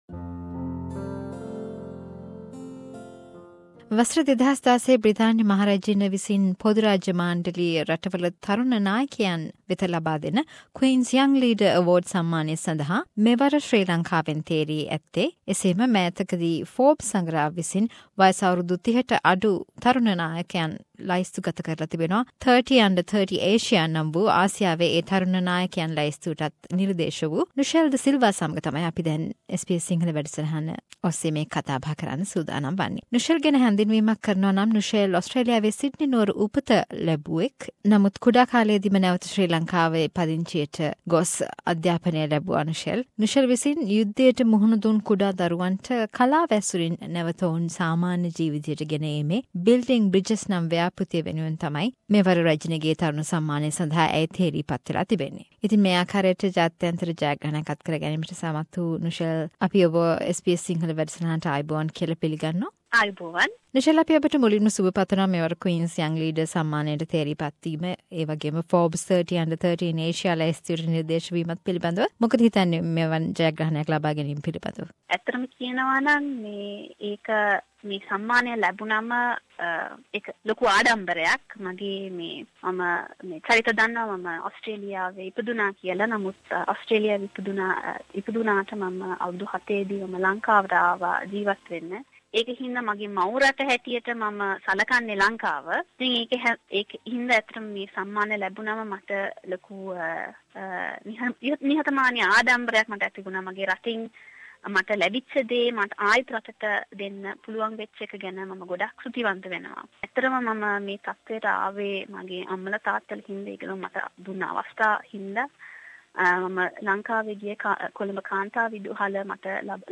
SBS සිංහල සේවය සමග කල පිළිසඳර